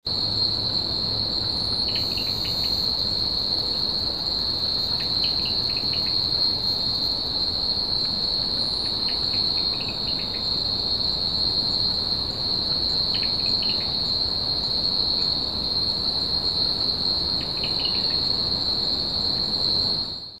Chalcorana cf. labialis
Copper-cheeked Frog     Macritchie, Singapore, 04 Jun 2018, 1100 hrs.
Heard calling from a tangle of vegetation in an inlet of Macritchie Reservoir, during a hot, sunny morning. The call is very quiet, so significant gain has been applied to the recording.
chalcorana-cf- labialis.mp3